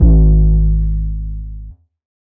808 Kick 15_DN.wav